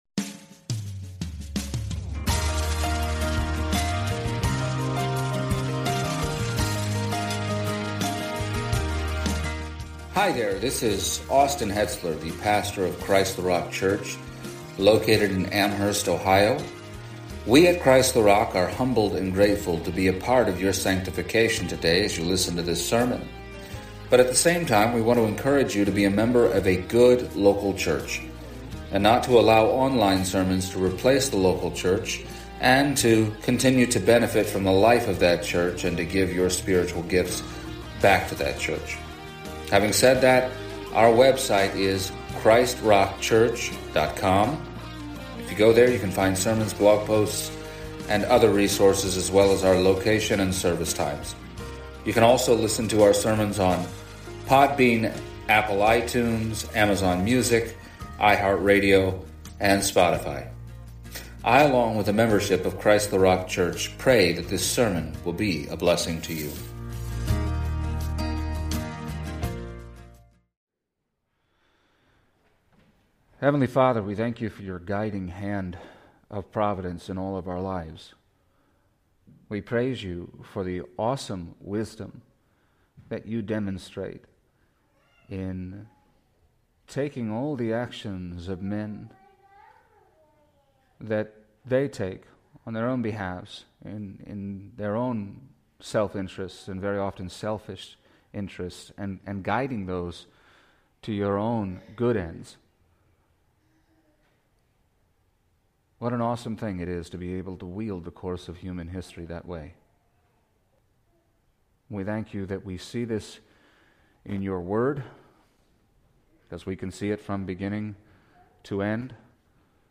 Exposition of the Book of Acts Passage: Acts 24:1-21 Service Type: Sunday Morning “Providence